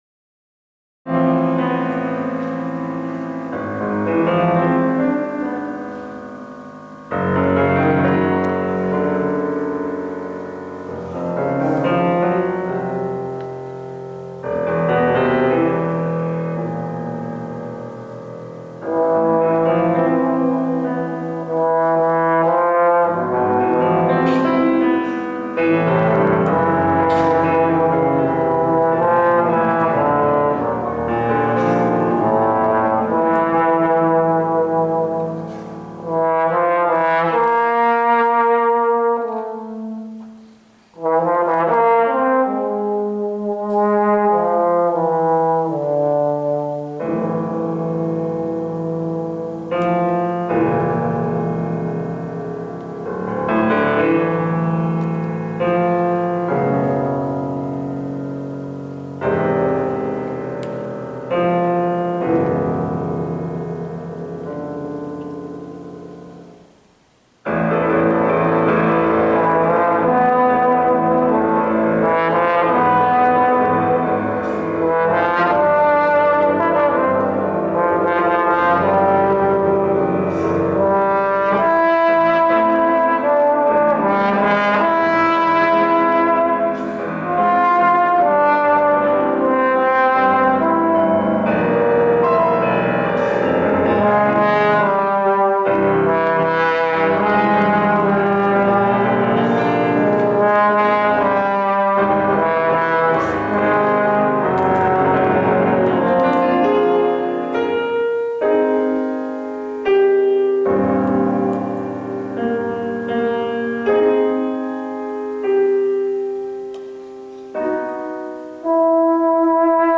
Voicing: Trombone Solo